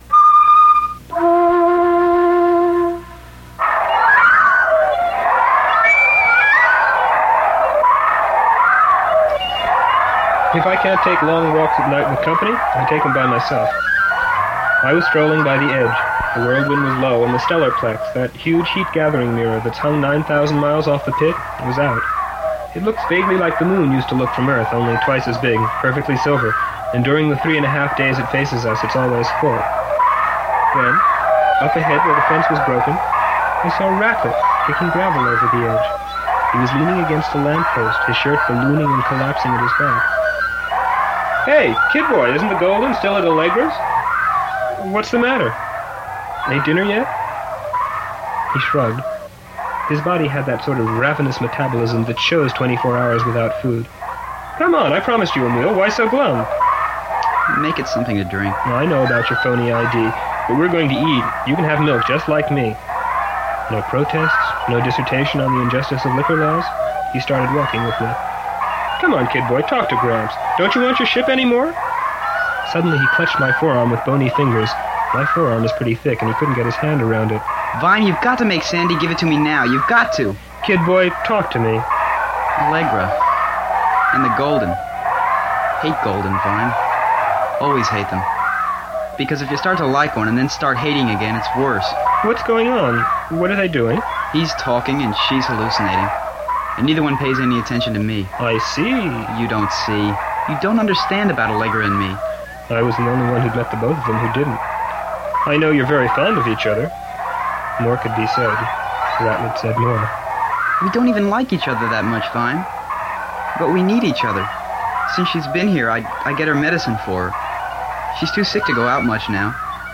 WARNING: The source tape (and this digitization) suffers from variable levels and occasional noise and clipping.